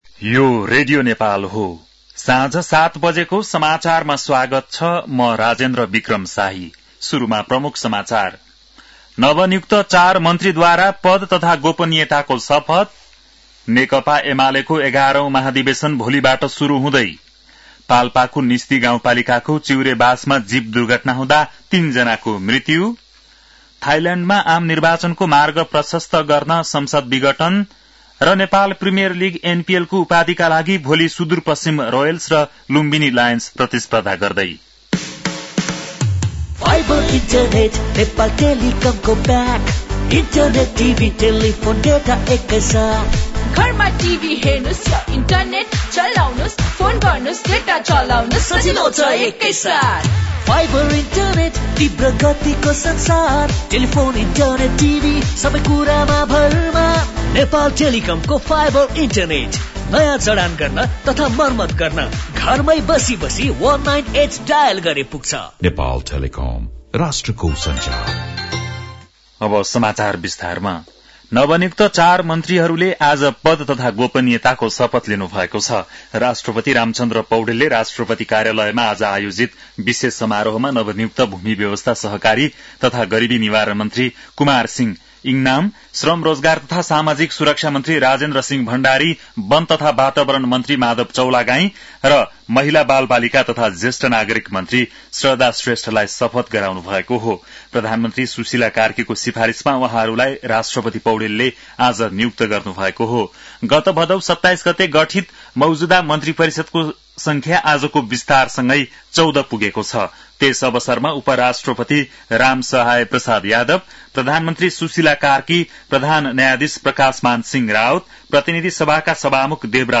बेलुकी ७ बजेको नेपाली समाचार : २६ मंसिर , २०८२
7-pm-nepali-news-8-26.mp3